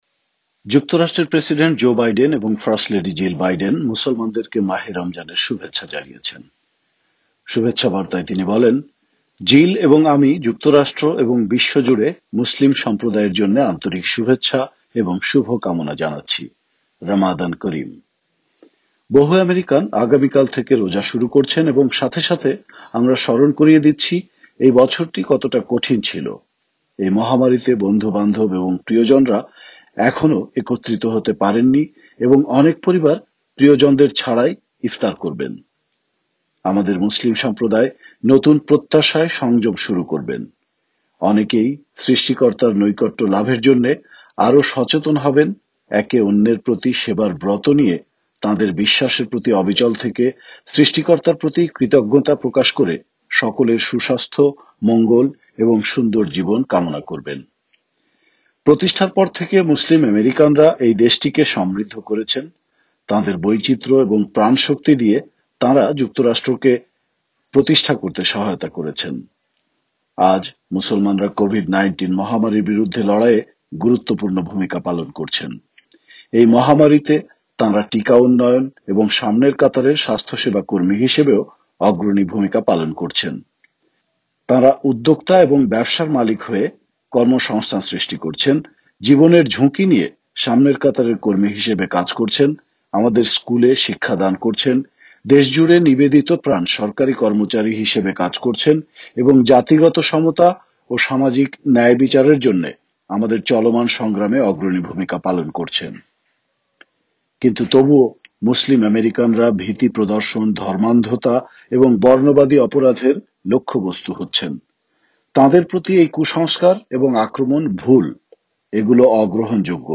রমজান উপলক্ষে প্রেসিডেন্ট বাইডেন ও ফার্স্ট লেডির শুভেচ্ছা বার্তা